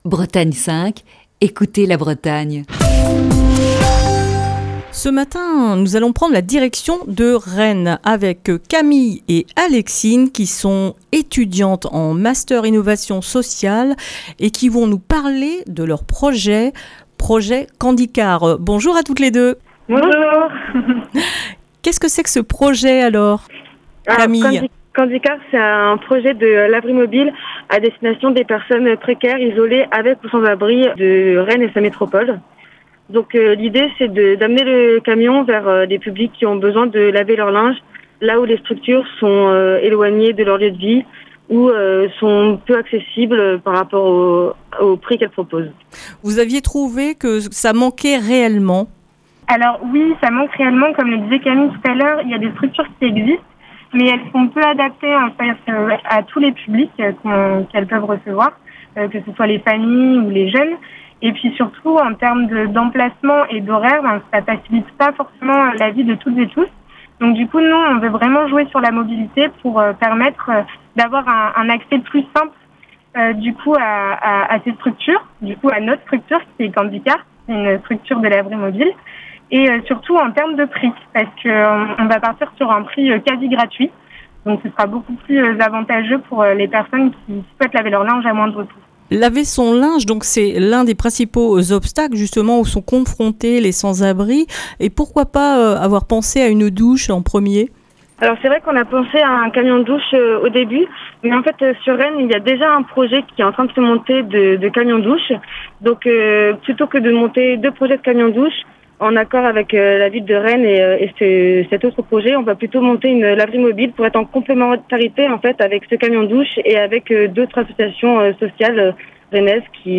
est au téléphone